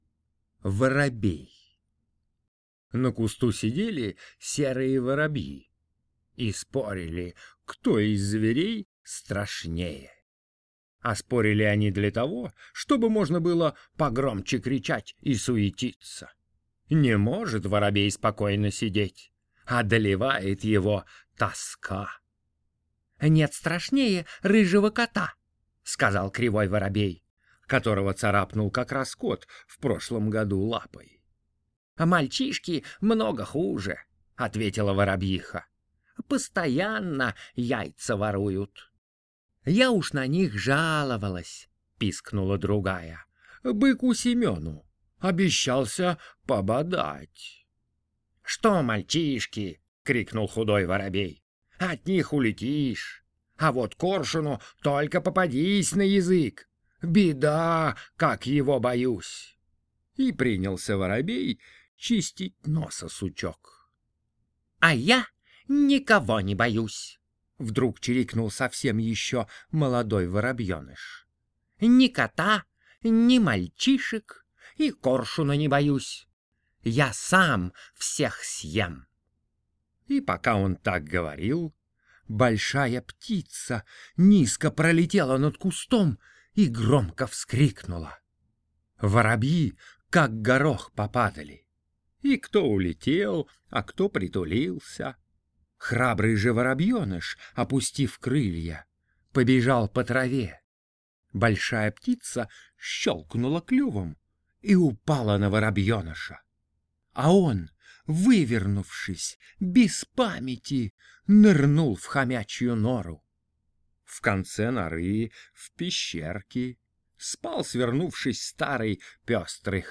Воробей - аудиосказка Алексея Толстого - слушать онлайн | Мишкины книжки
Воробей – Толстой А.Н. (аудиоверсия)